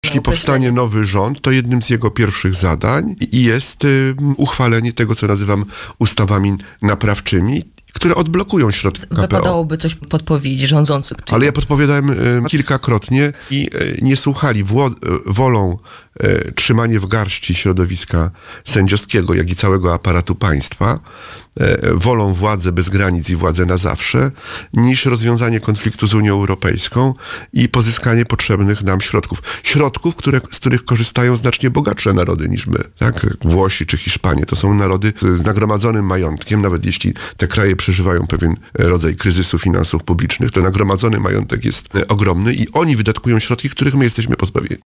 Mam nadzieję, że Polacy na to oszustwo się nie nabiorą, -Marzę o tym, by za jakiś czas mur na granicy białoruskiej przestał istnieć, – W szkołach istnieją toksyczne relacje – mówił w audycji „Poranny Gość” senator K.M Ujazdowski.